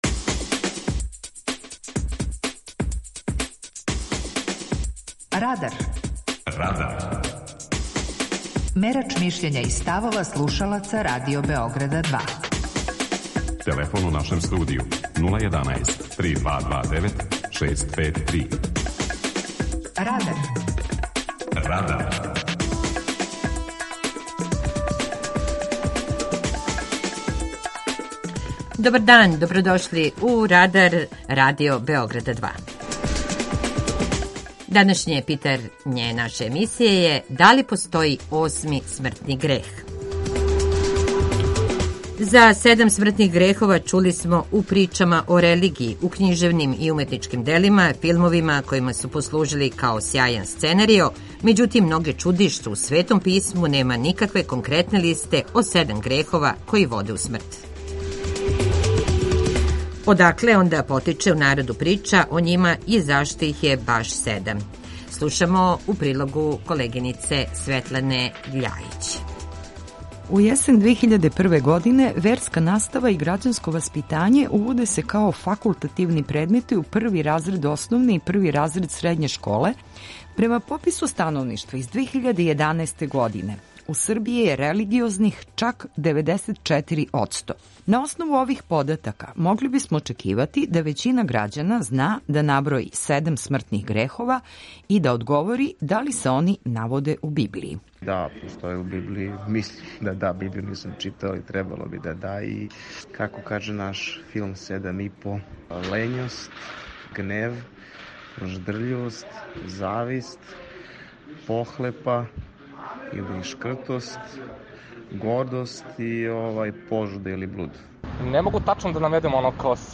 Питање Радара: Да ли постоји осми смртни грех? преузми : 18.53 MB Радар Autor: Група аутора У емисији „Радар", гости и слушаоци разговарају о актуелним темама из друштвеног и културног живота.